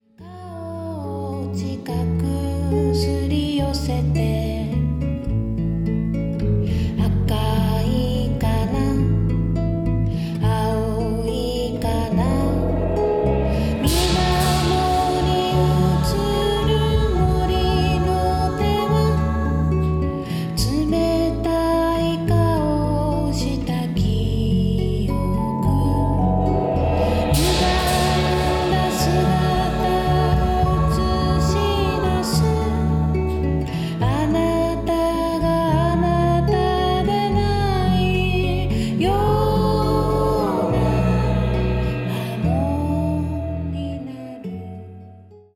幻想の中に真理がある、童話のような歌たち。
サイケデリックであるけれど、アングラ過ぎない。